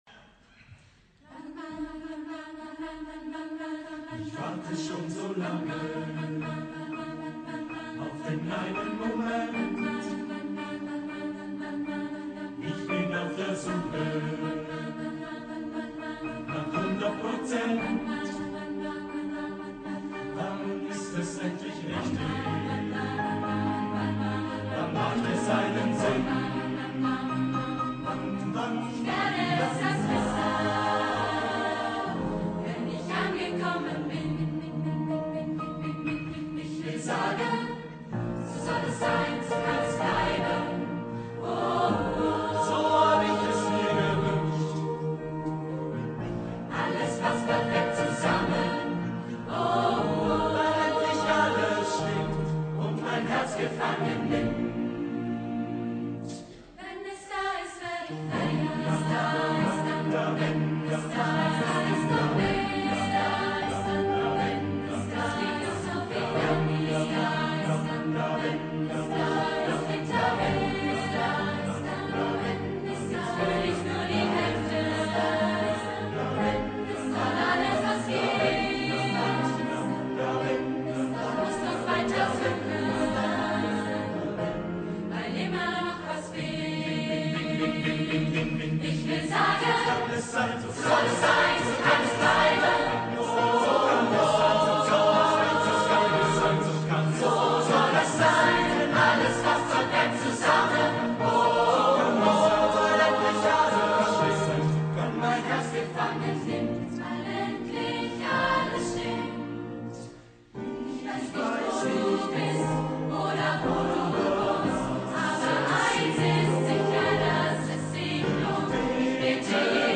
So soll es bleiben – Chorversion voices4you